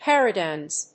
• / ˈpɛrʌˌdaɪmz(米国英語)
• / ˈperʌˌdaɪmz(英国英語)